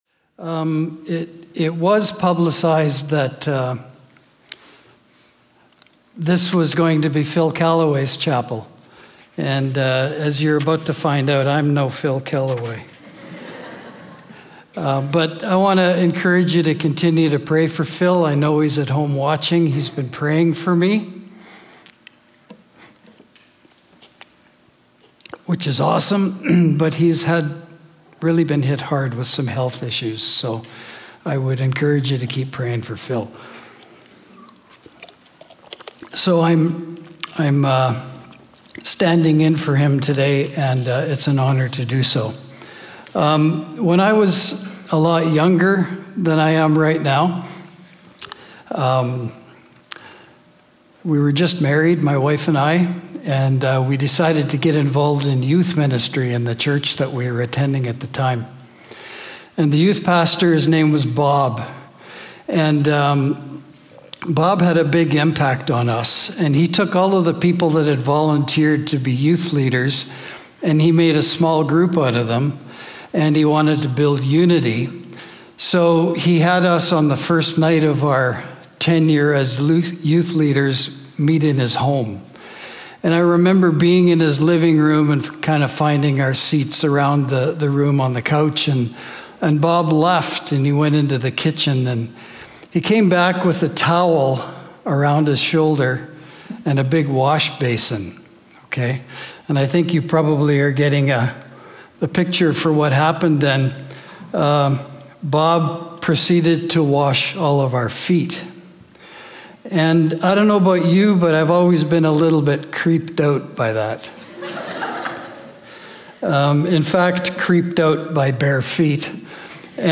We welcome you to join us every week for a new Community Chapel service here at Prairie College.